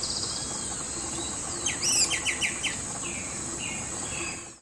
Anambé Común (Pachyramphus polychopterus)
Nombre en inglés: White-winged Becard
Fase de la vida: Adulto
Localidad o área protegida: Reserva Natural del Pilar
Condición: Silvestre
Certeza: Vocalización Grabada